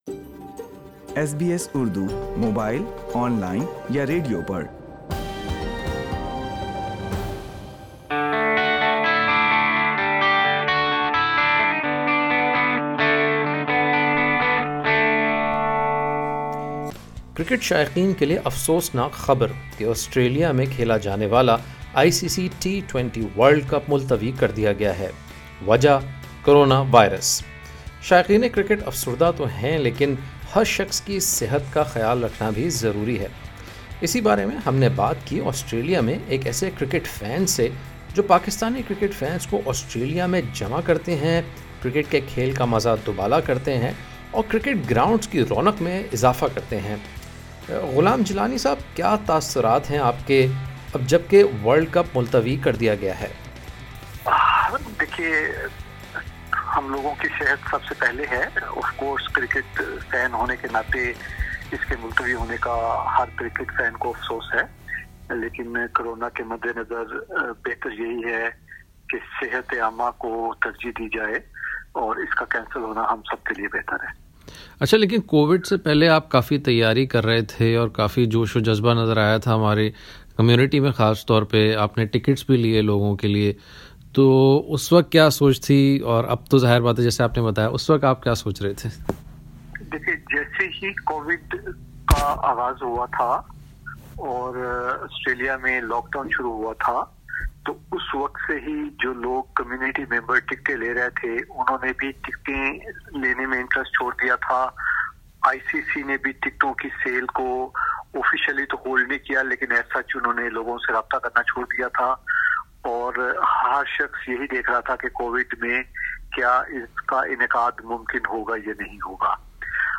world_cup_t20_postponed_interview.mp3